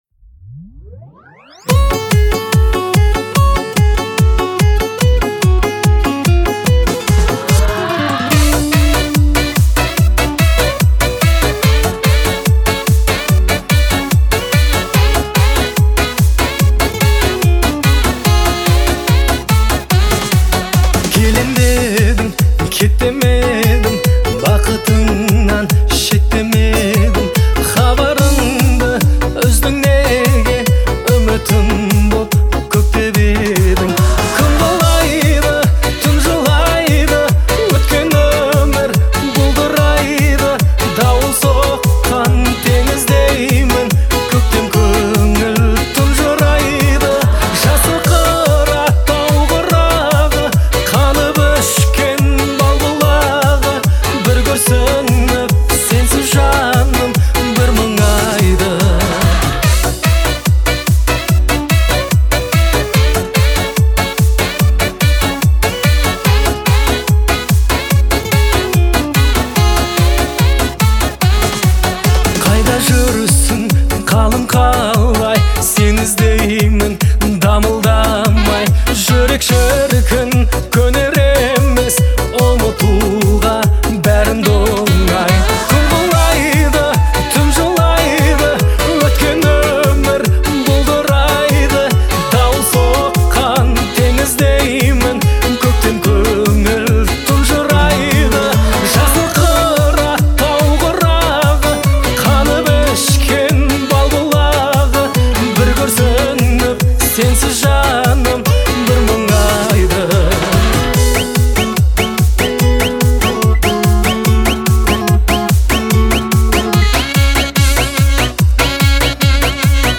soulful баллада